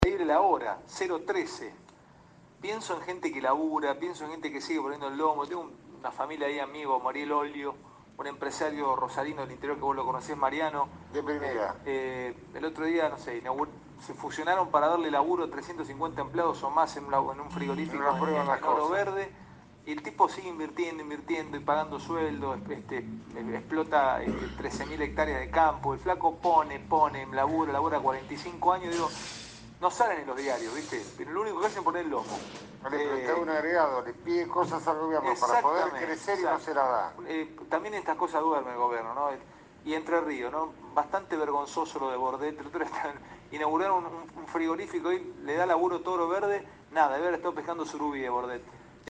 Fue este miércoles, en el Programa “Animales Sueltos” que conduce Alejandro Fantino.